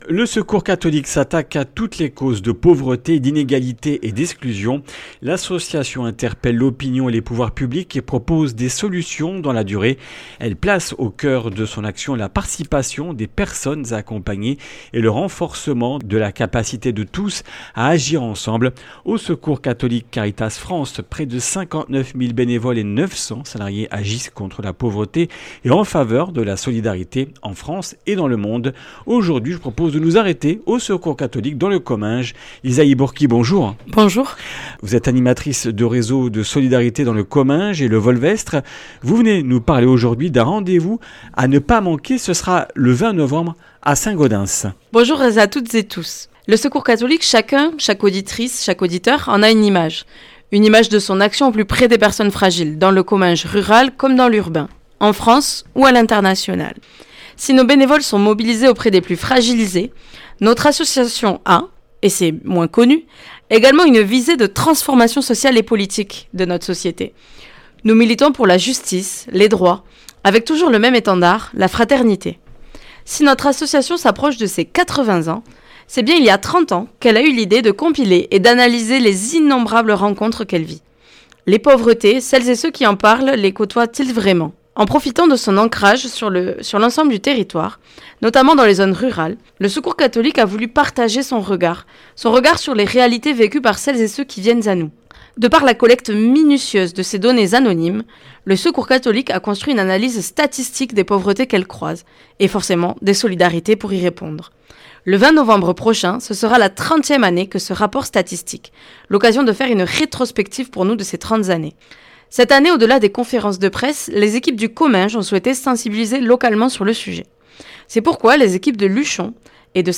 Comminges Interviews du 12 nov.
Une émission présentée par